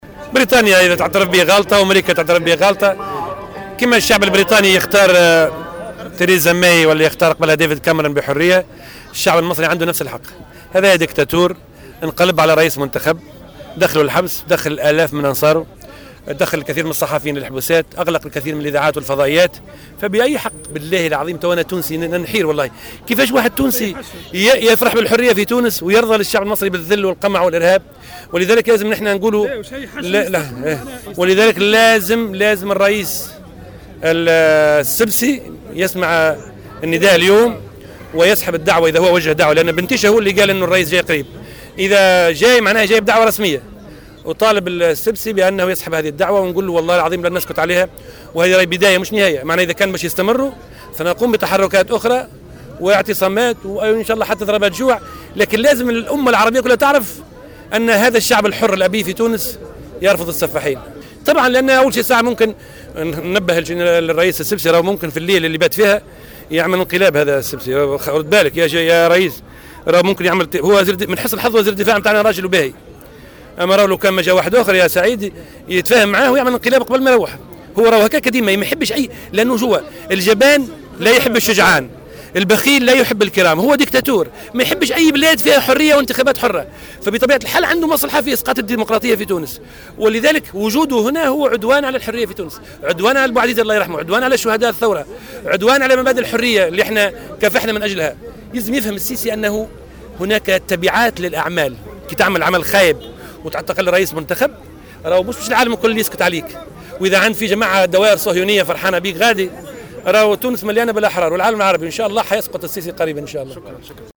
ووصف الحامدي، في تصريح للجوهرة أف أم، خلال مظاهرة مناهضة لزيارة الرئيس المصري إلى تونس، نظمها حزبه اليوم السبت 18 فيفري 2017، وسط العاصمة، السيسي بـ"الدكتاتور الإنقلابي والسفاح" الذي لا ينبغي لدولة ديمقراطية كتونس أن تستقبله، مشيرا إلى أن "تيار المحبة" سيواصل تحركاته في حال لم يسحب قائد السبسي دعوته للسيسي.